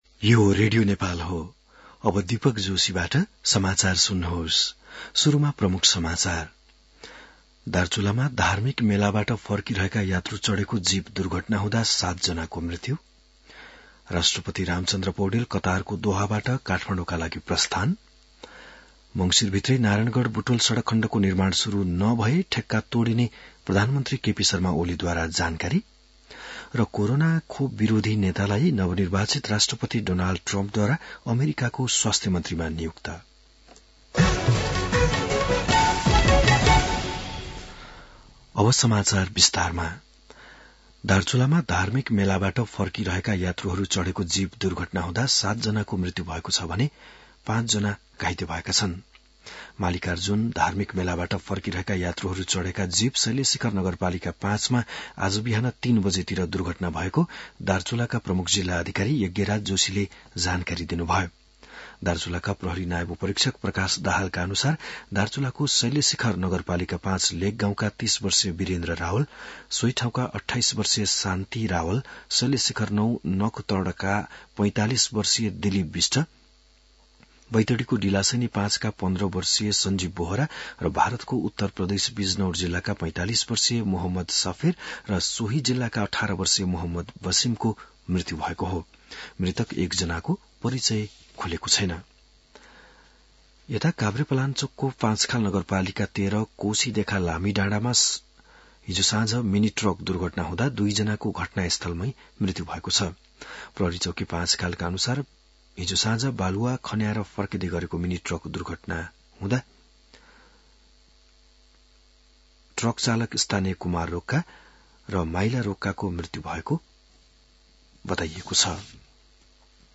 बिहान ९ बजेको नेपाली समाचार : १ मंसिर , २०८१